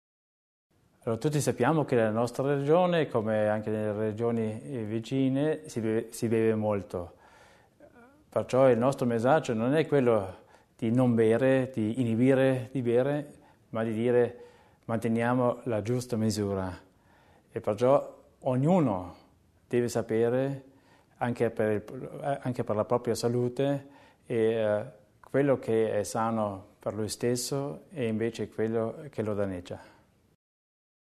La quarta fase della campagna di prevenzione contro l’abuso dell’alcol è stata presentata questa mattina nel corso di una conferenza stampa dall’assessore provinciale alla sanità ed alle politiche sociali, Richard Theiner.